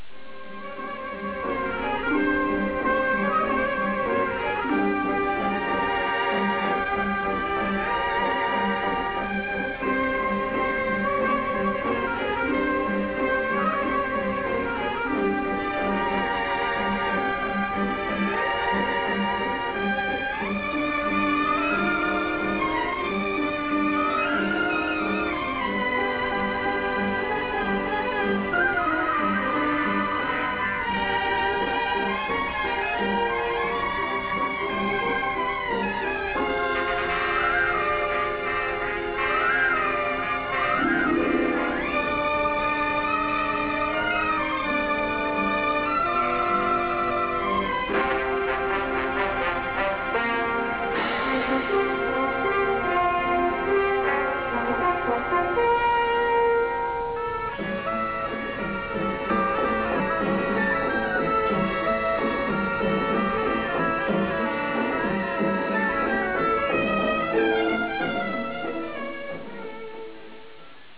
• Musica
Original track music